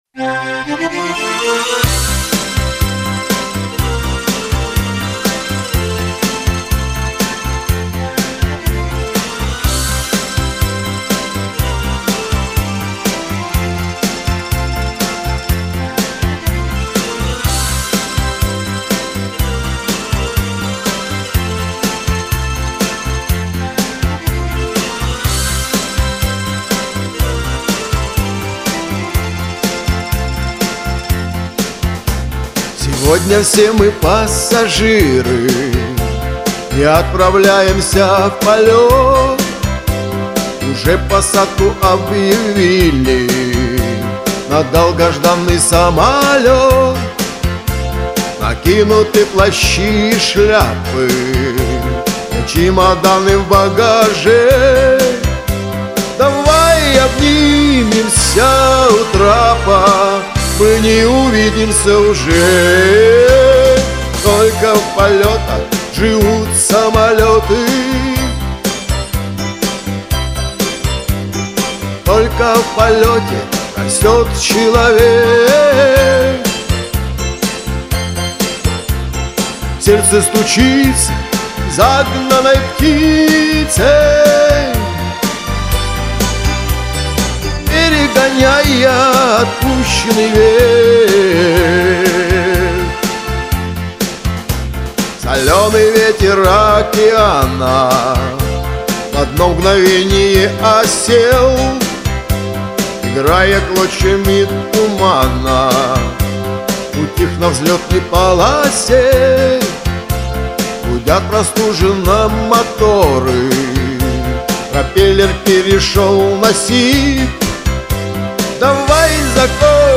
Минус - один и тот же.
Качество записи - одинаково хорошее.
Разница лишь в тембровой окраске и в мелких нюансах.